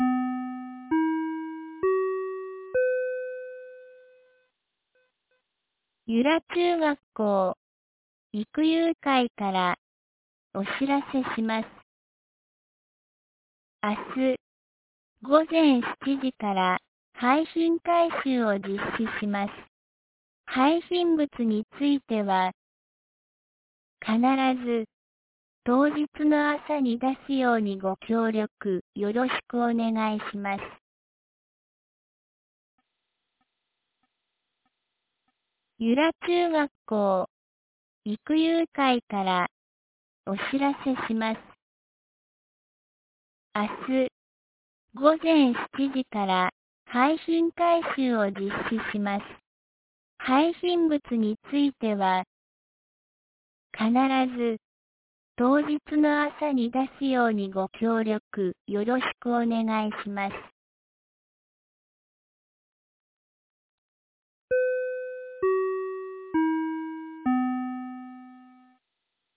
2025年08月02日 17時11分に、由良町から横浜地区、里地区、南地区、阿戸地区、網代地区、江ノ駒地区、門前地区、中地区、畑地区、神谷地区、糸谷地区、吹井地区、黒田地区、柳原地区へ放送がありました。